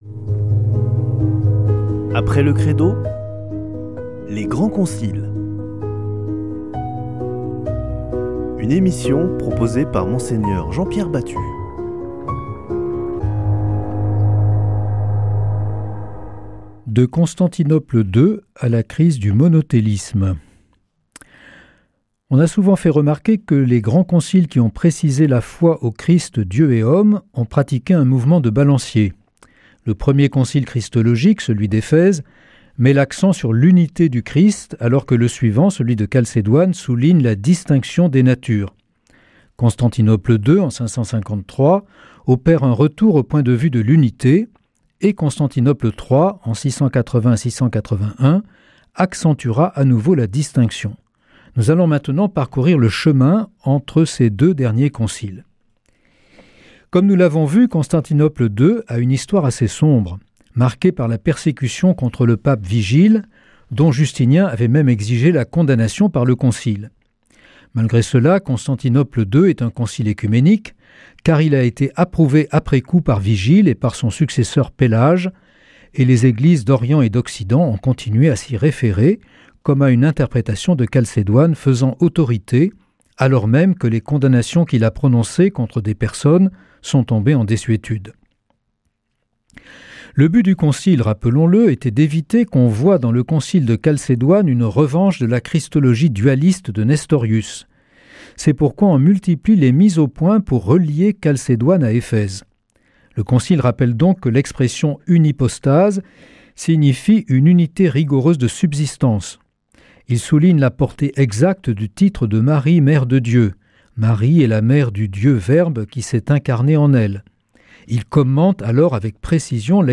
Une émission présentée par Mgr Jean-Pierre Batut Evêque auxiliaire de Toulouse